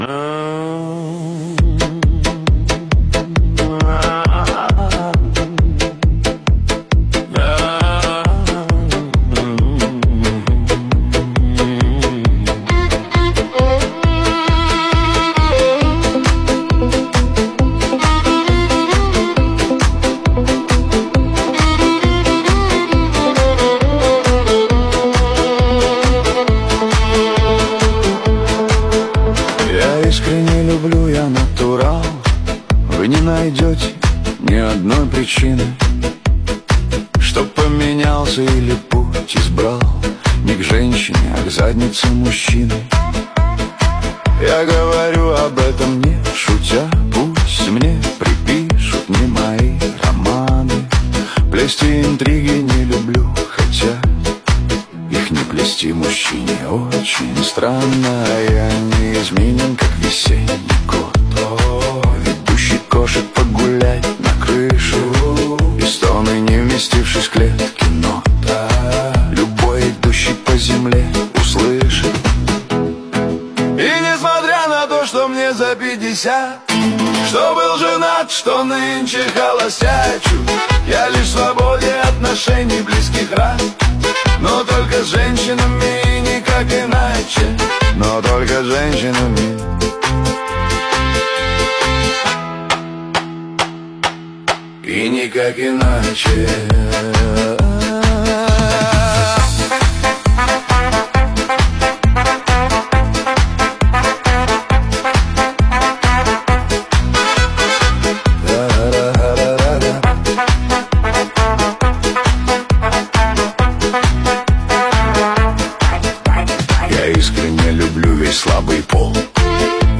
Поп музыка, Русские поп песни